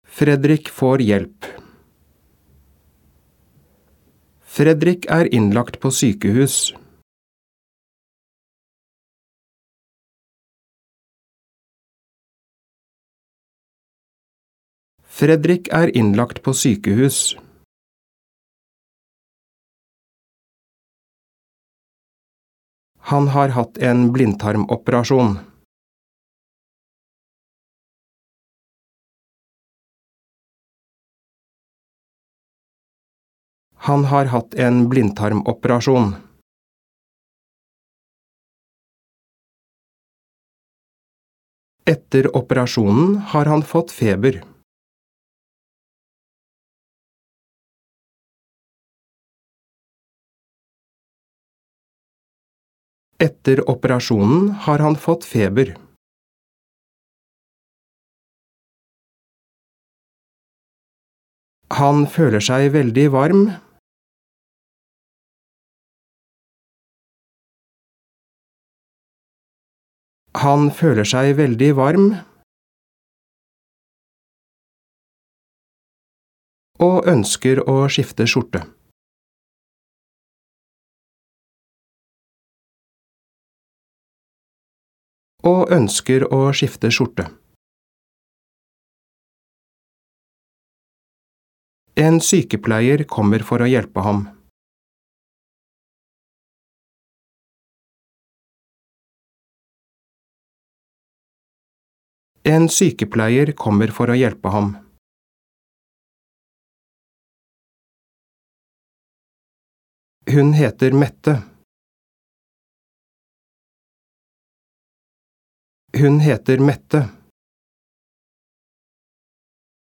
Diktat leksjon 2
• Andre gang leses hele setninger og deler av setninger.